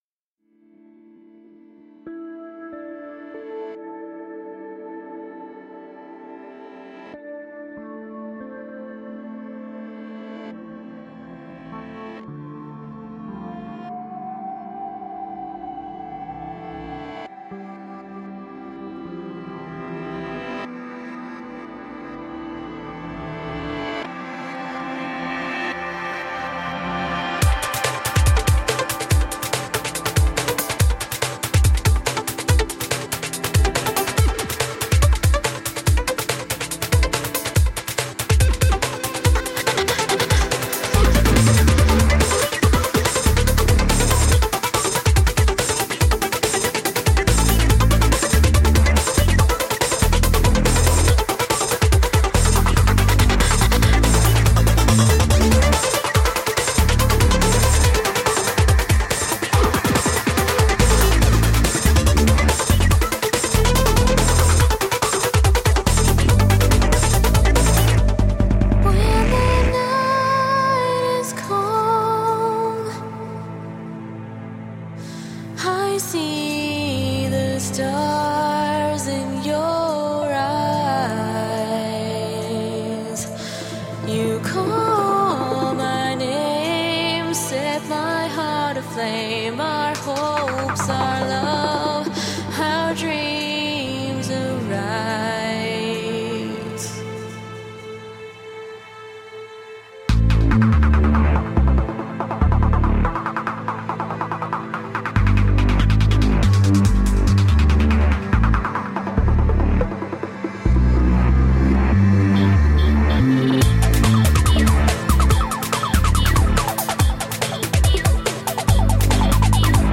Tagged as: Electro Rock, Ambient